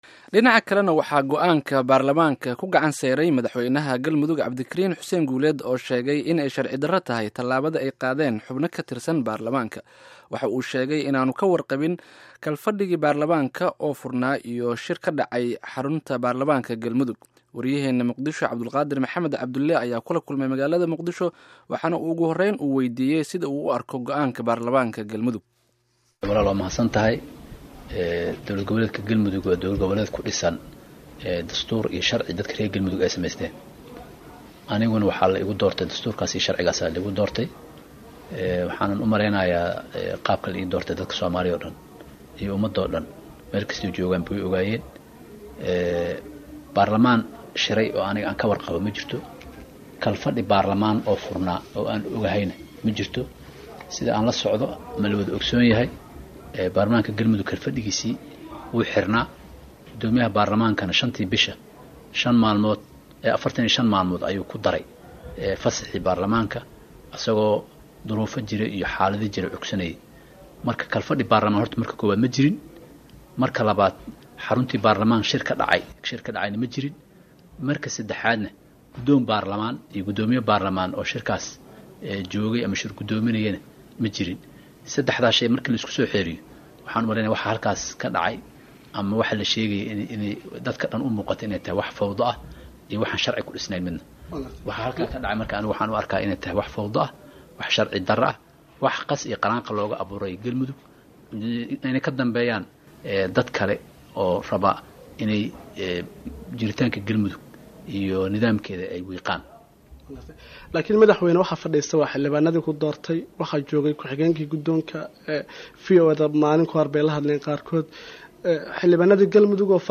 Wareysi: Cabdikariim Guuleed